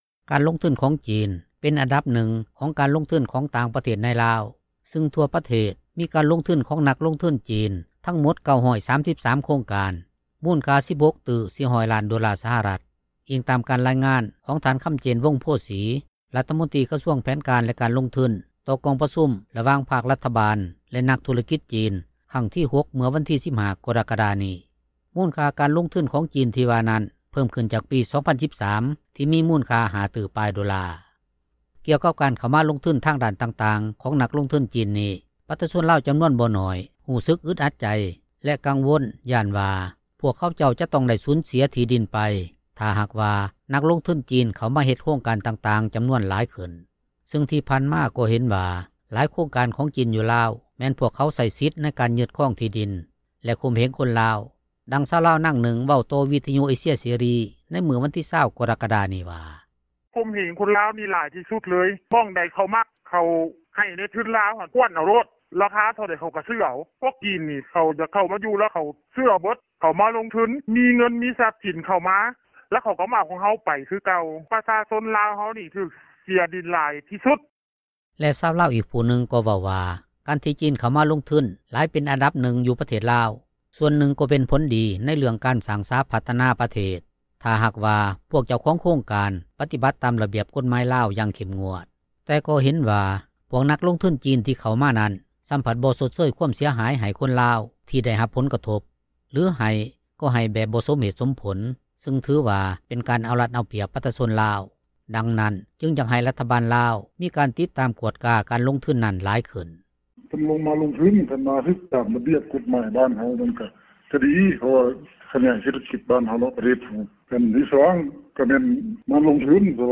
ດັ່ງຊາວລາວນາງນຶ່ງ ເວົ້າຕໍ່ວີທຍຸເອເຊັຽເສຣີໃນມື້ວັນທີ 20 ກໍຣະກະດານີ້ວ່າ: